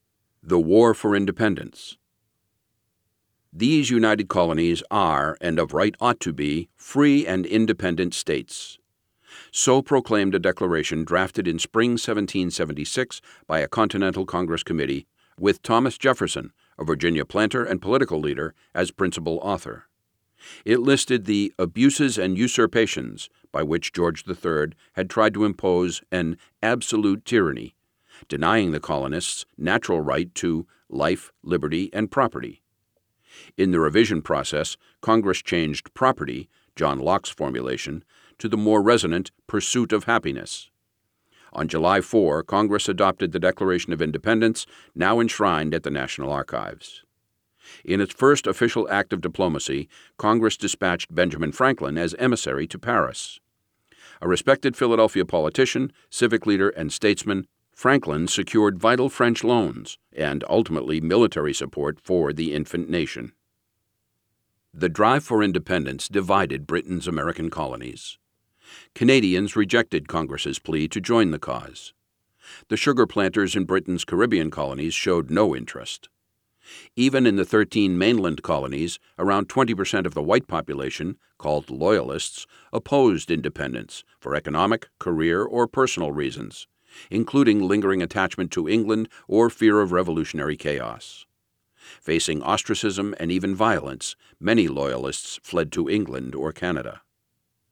Audio Books